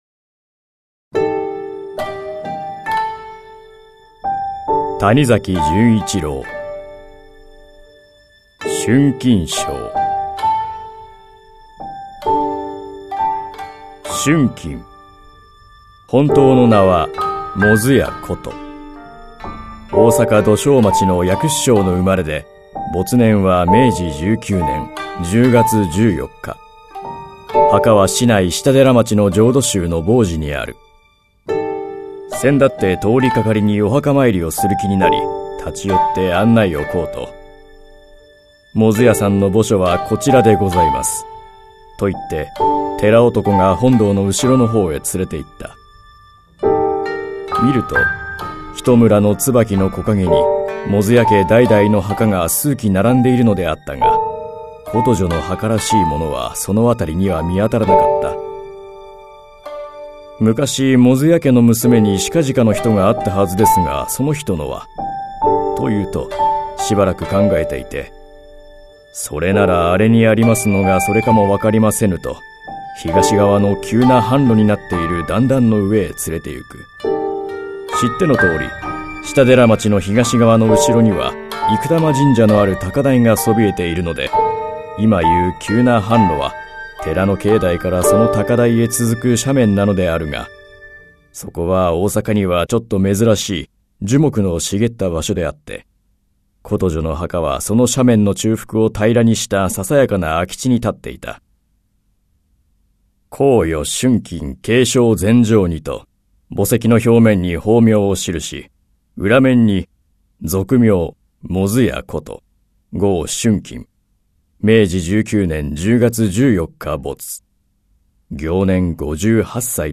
[オーディオブック] 谷崎潤一郎「春琴抄」